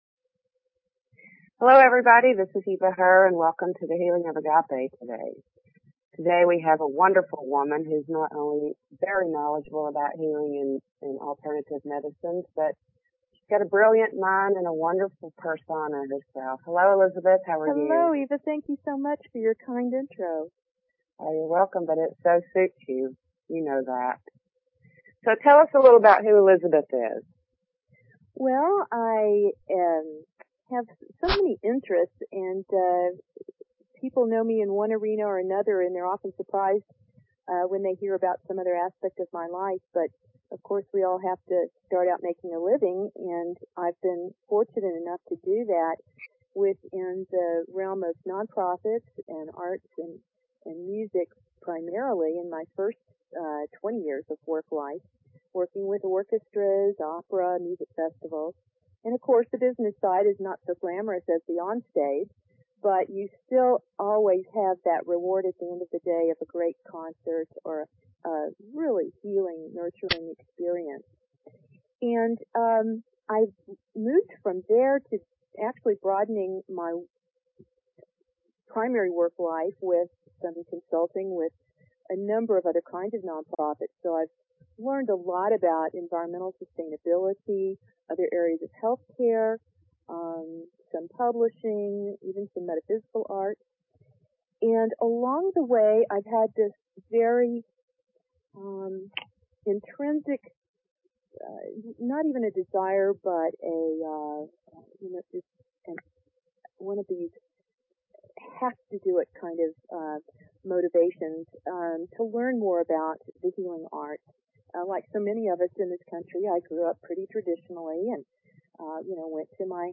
Talk Show Episode, Audio Podcast, The_Infinite_Consciousness and Courtesy of BBS Radio on , show guests , about , categorized as